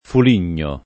Fuligno [ ful & n’n’o ]